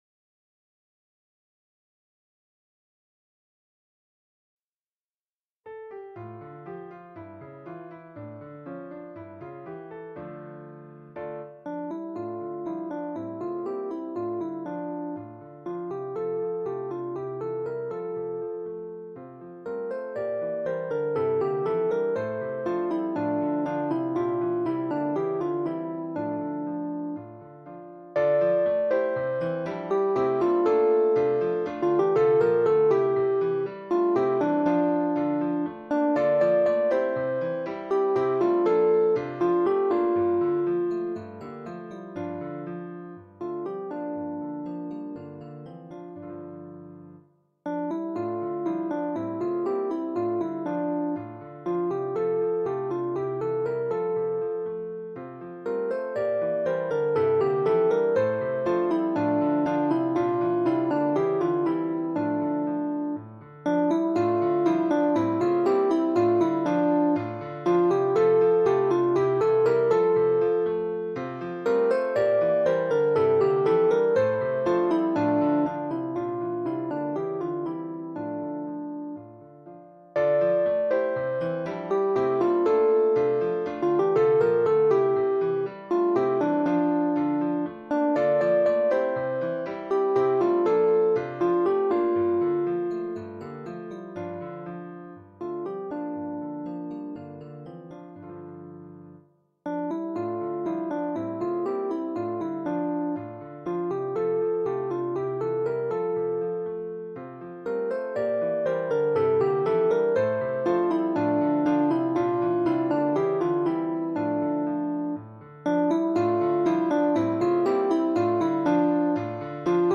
MP3 version instrumentale
3 Voix